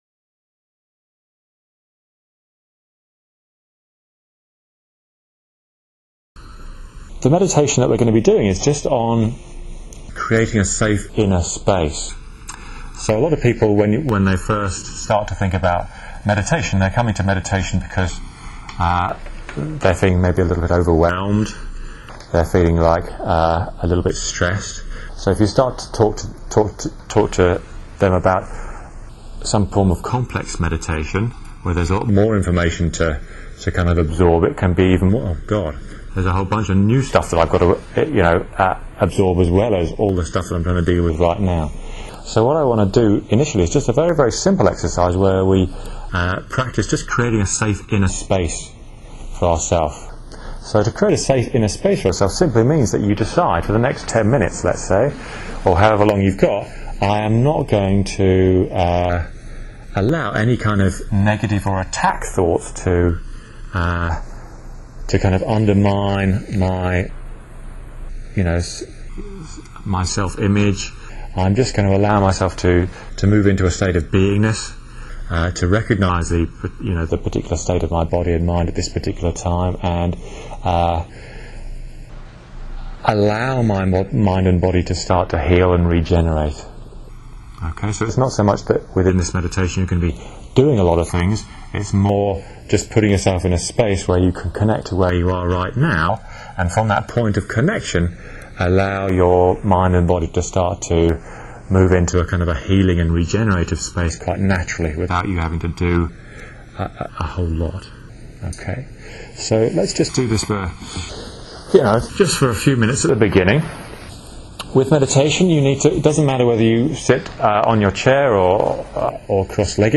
Safe Space NUS in Stereo